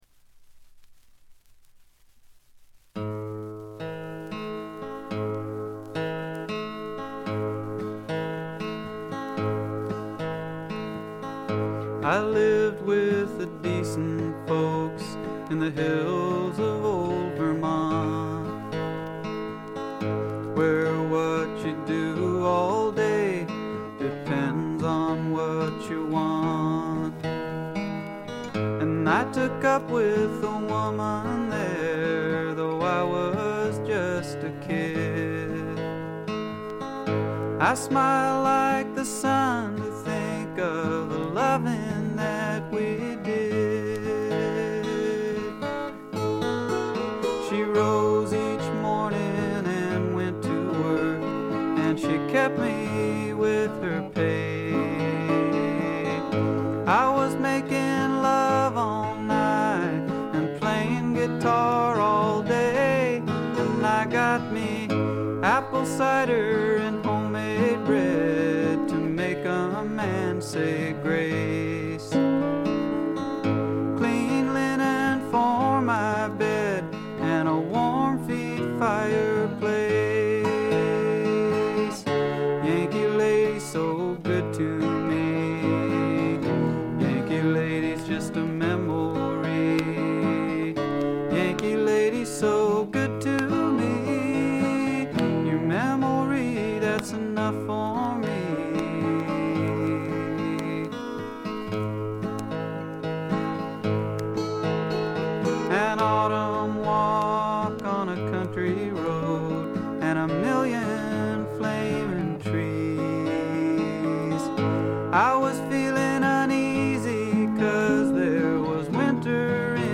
A2中盤でプツ員の周回ノイズ10回ほど、B1序盤でプツ音2-3回ほど。
試聴曲は現品からの取り込み音源です。